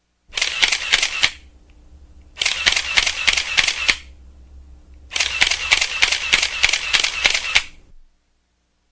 Freesound分层音效 " 摄像机混音
描述：许多不同摄像机点击的分层立体声录音。
Tag: 相机咔嚓咔嚓按扣 电影 摄像头 搭配 快门 单反相机 摄影 照片 数码单反相机 会议